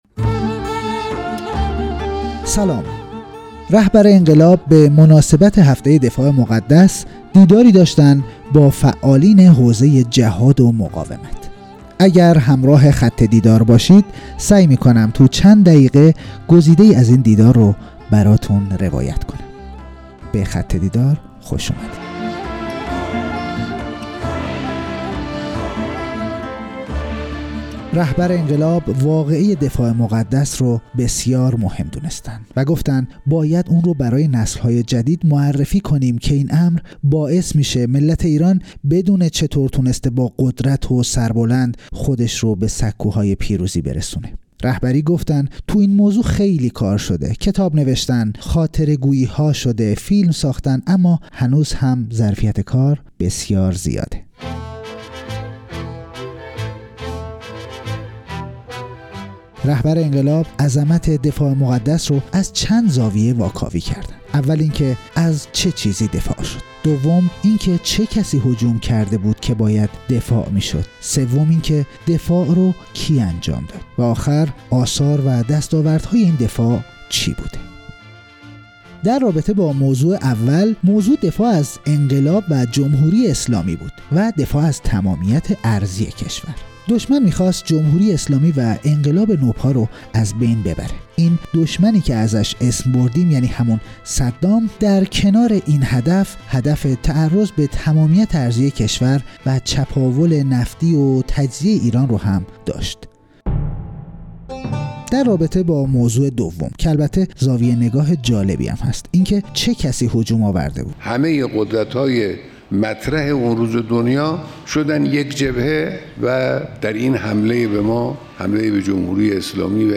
بیانات در دیدار طلایه‌داران و فعالان حوزه‌های مختلف دفاع مقدس